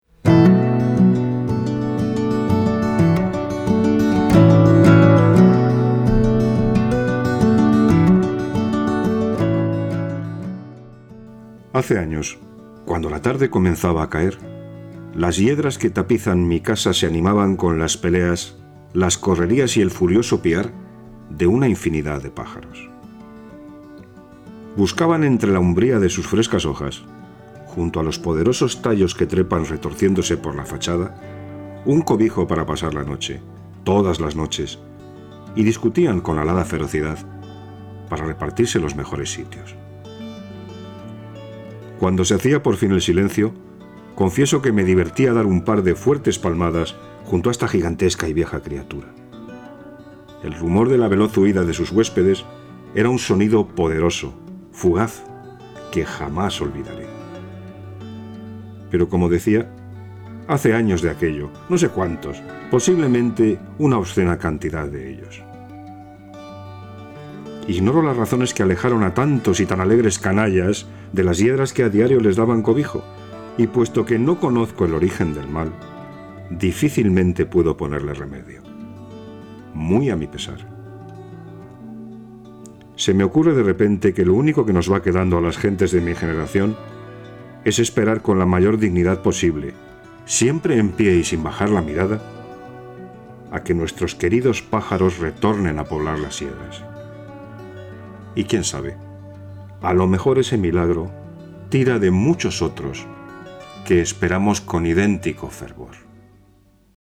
A mature and authoritative, reliable and cordial voice.
Castelanian
Middle Aged